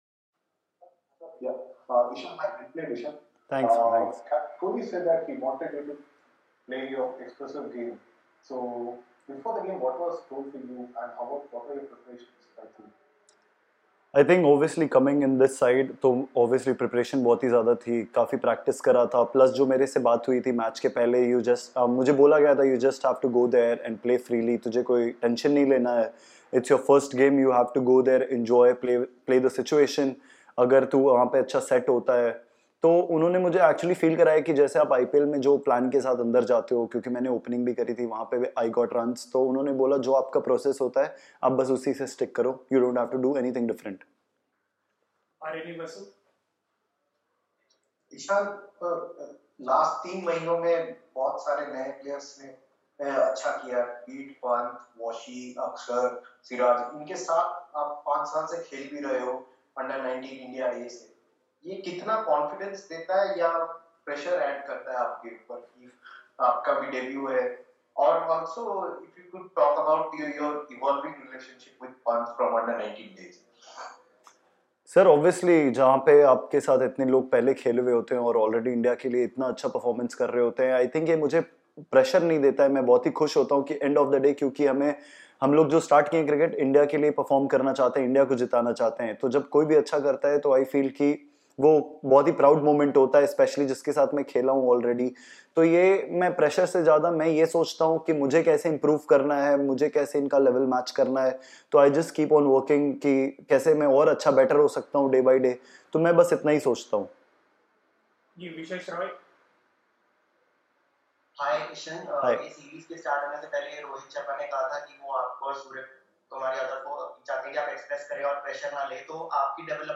Ishan Kishan, Member, Indian Cricket Team, addressed a virtual press conference after the 2nd Paytm India-England T20I played at the Narendra Modi Stadium in Ahmedabad.